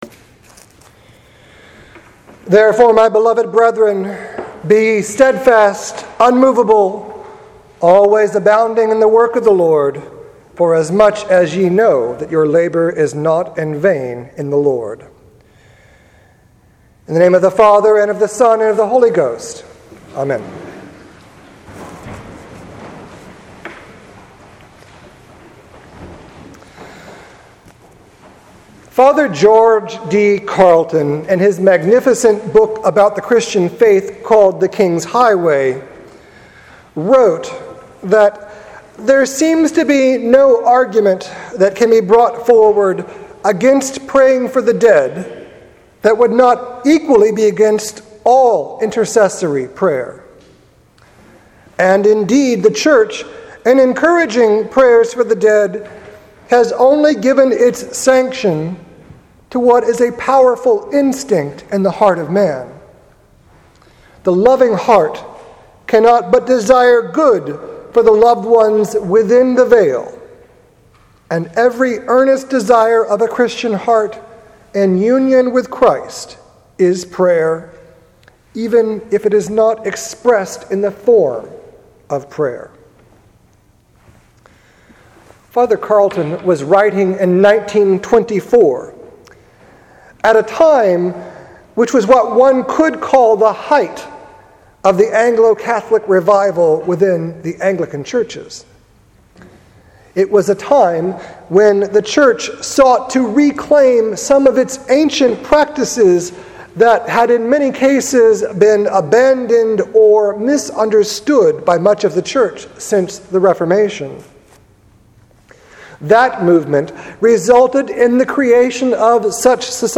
Sermon given on November 2nd, 2016 at the requiem mass for All Souls’ Day.